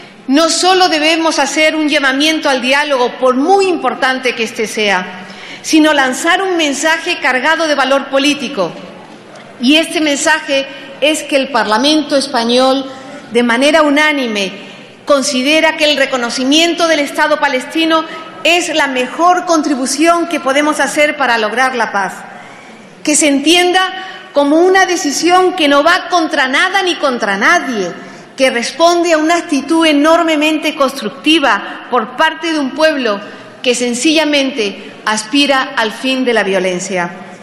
Fragmento de la intervención de Trinidad Jiménez defendiendo que el Parlamento español apruebe el reconocimiento del estado de Palestina 18/11/2014